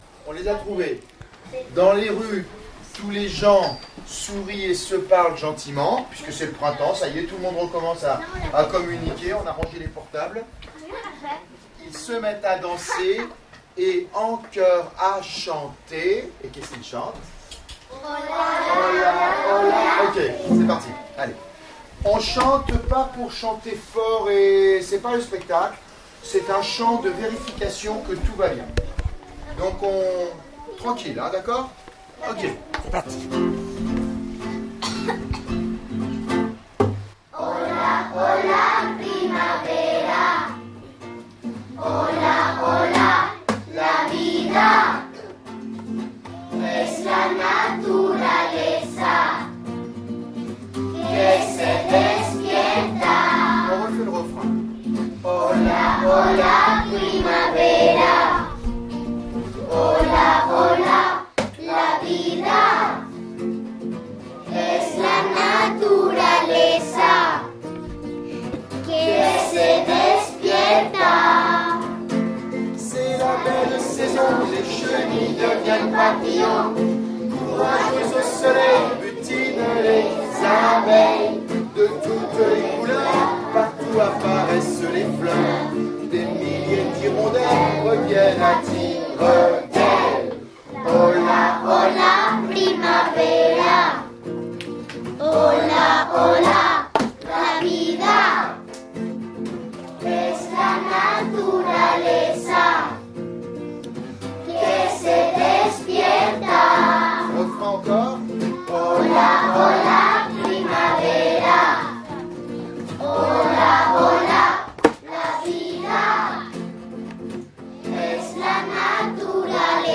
02/03/2017 à 16h 15| 4 mn | musique| primaire | événement |télécharger
La chanson du Printemps par les CE1-1 et CE1-5